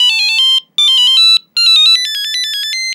Tono de teléfono móvil 16